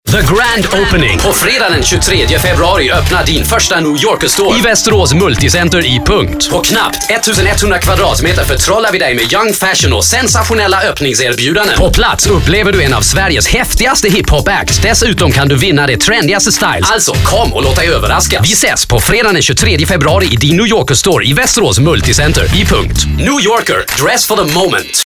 Sprecher schwedisch.
Werbesprecher, Synchronsprecher...
schwedisch
Sprechprobe: Sonstiges (Muttersprache):